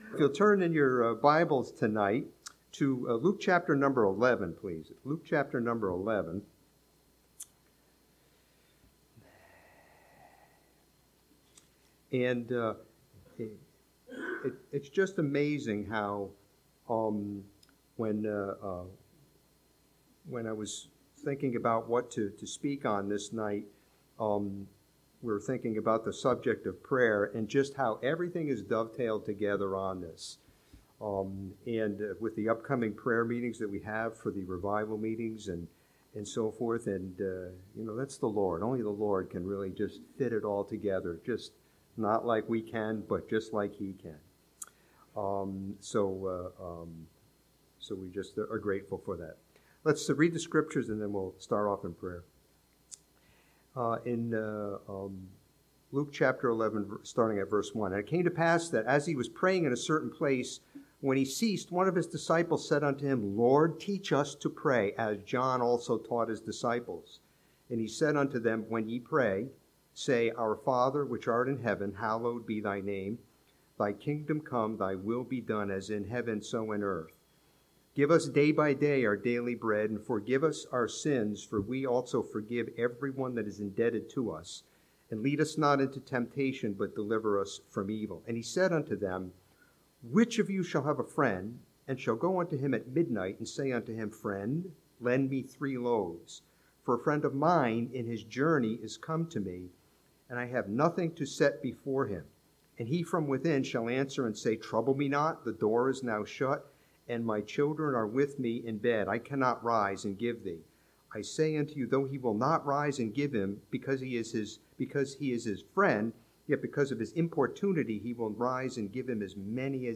This sermon from Luke chapter 11 challenges us with an examination of the prayer life of Christ as the supreme example for us.